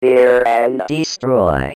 Tag: 140 bpm Electronic Loops Vocal Loops 295.49 KB wav Key : Unknown